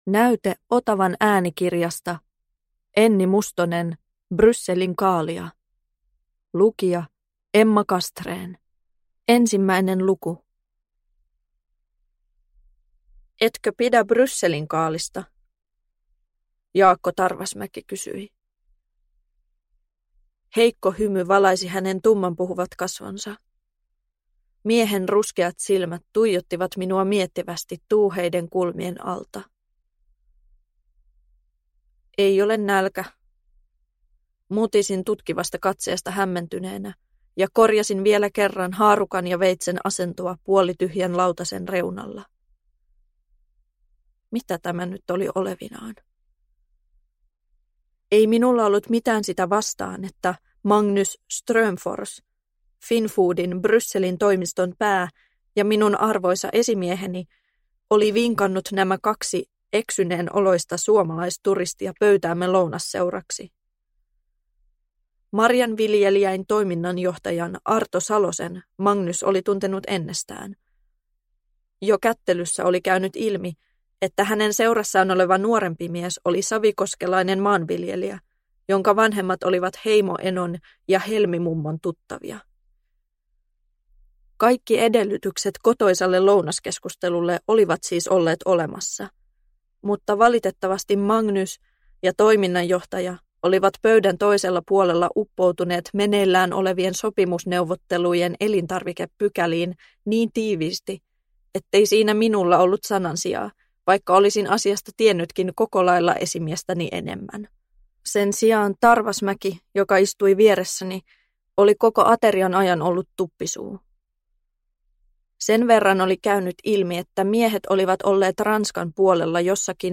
Brysselinkaalia – Ljudbok – Laddas ner